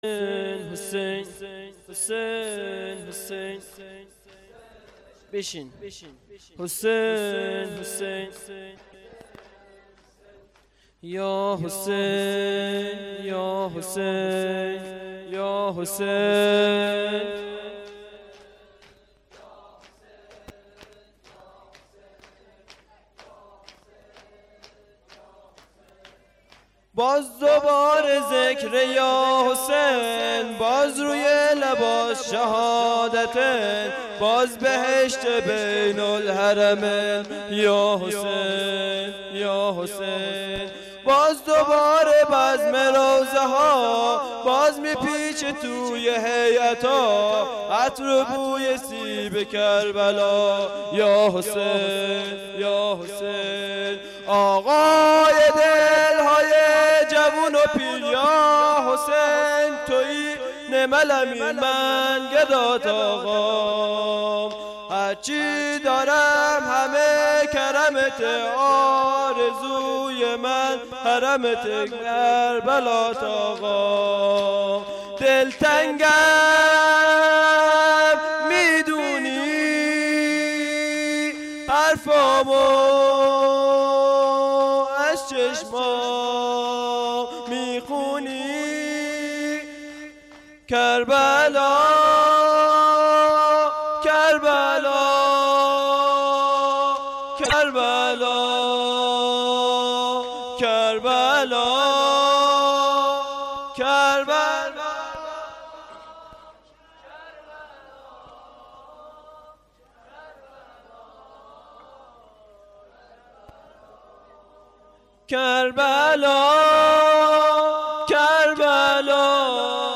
• روستای کرقند, سینه زنی شور, هیئت ثارالله